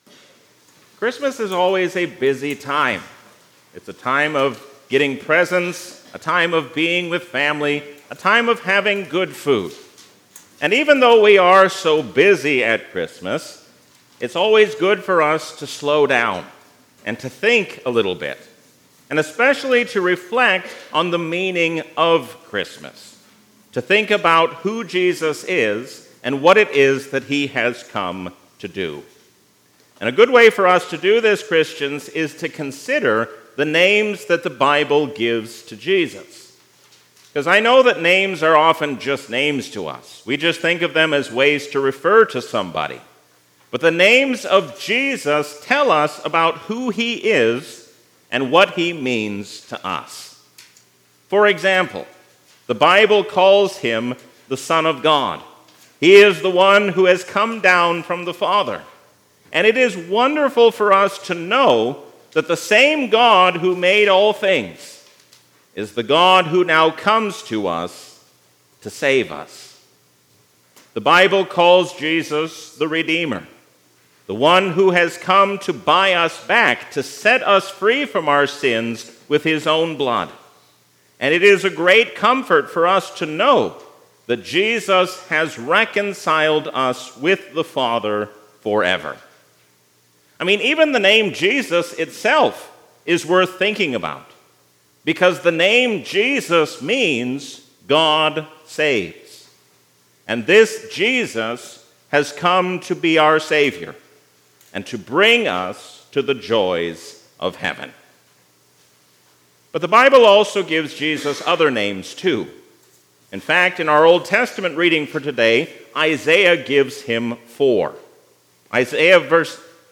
A sermon from the season "Christmas 2023." The names given to Jesus show us who He is and what He has come to do for us.